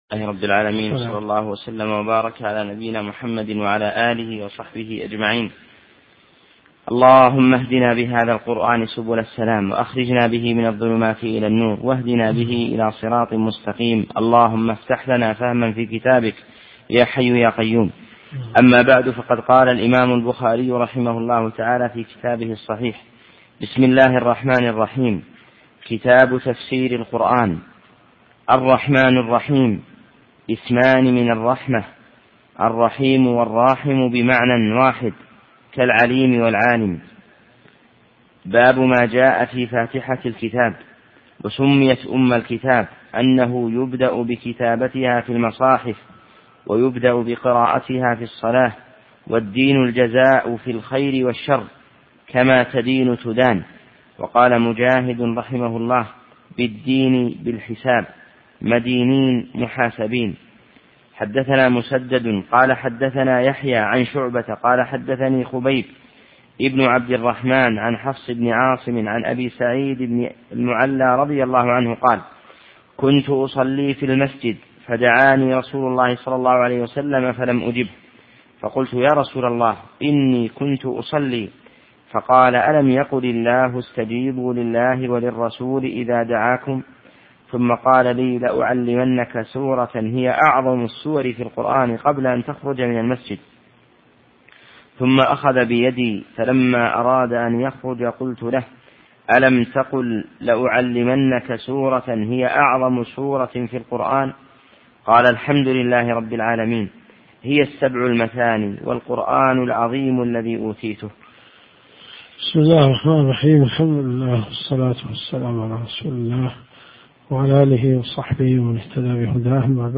الرئيسية الكتب المسموعة [ قسم الحديث ] > صحيح البخاري .